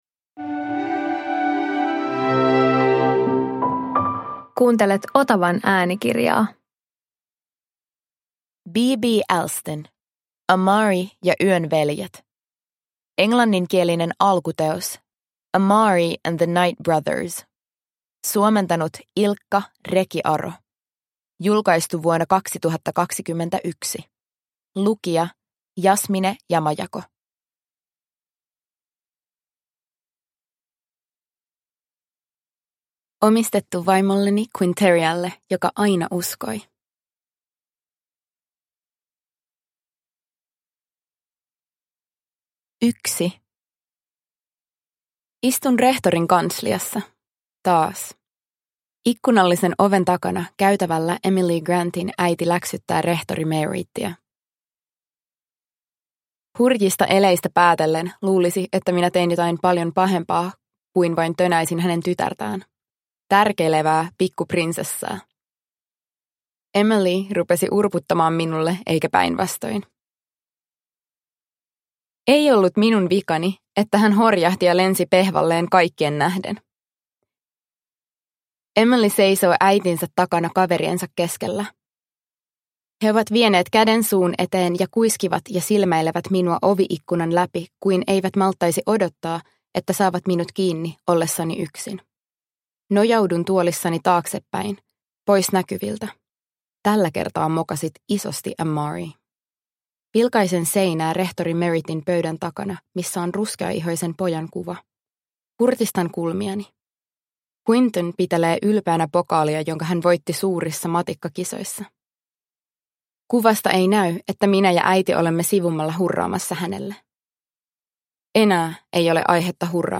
Amari ja yön veljet – Ljudbok – Laddas ner